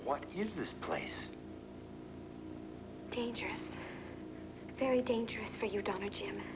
Memorable Dialog